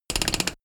Royalty free sounds: Office work